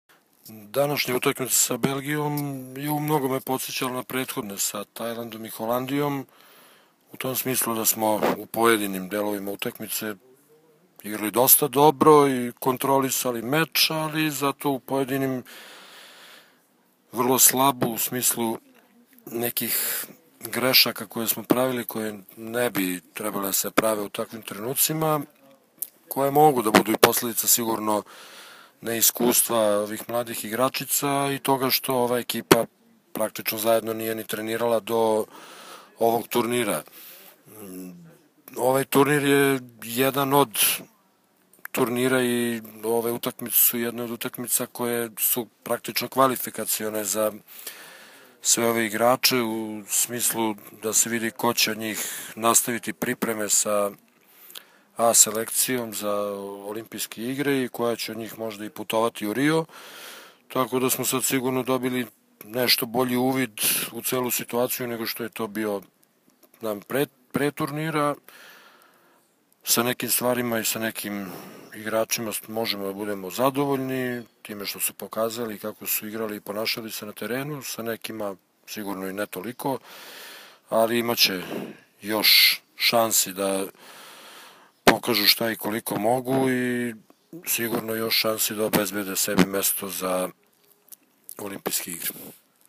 SENIORKE – 31. MONTRE VOLEJ MASTERS 2016.